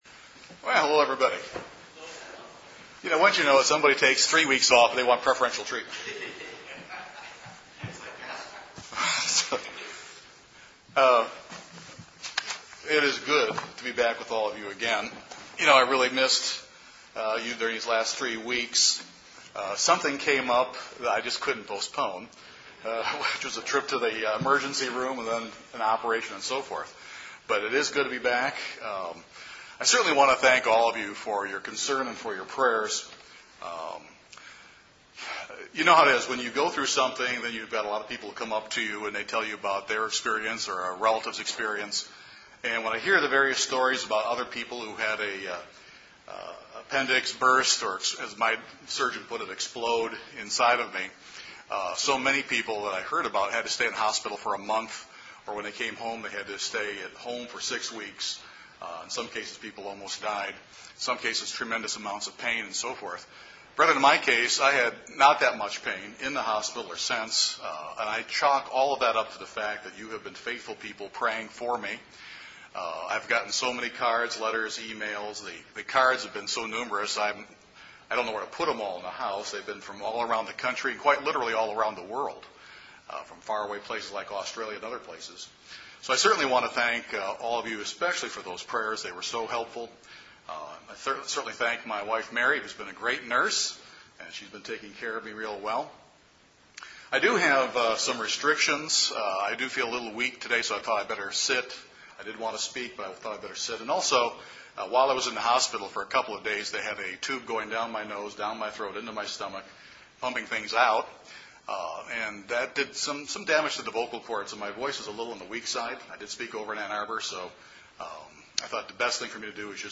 This sermon answers these important questions.